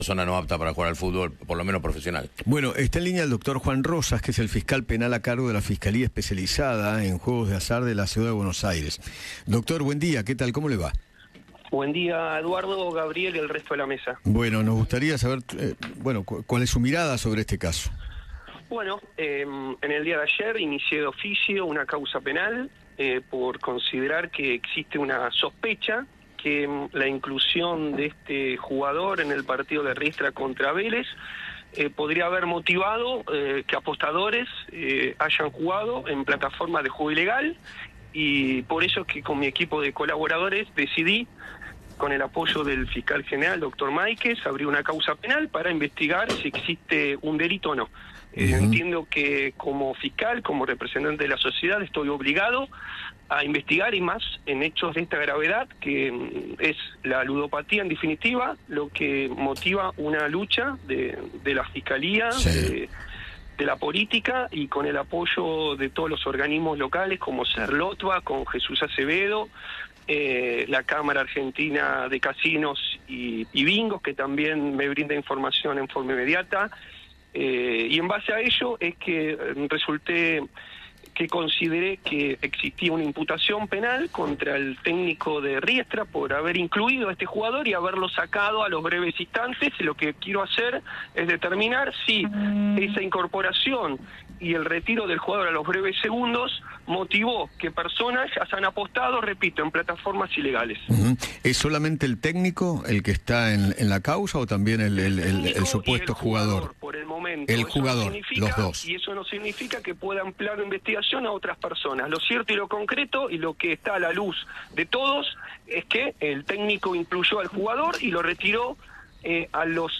Juan Rozas, responsable de la Fiscalía Especializada en Juegos de Azar, habló con Eduardo Feinmann y confirmó la acusación contra el streamer Spreen, así como también contra Cristian Fabbiani, director técnico del equipo del Bajo Flores.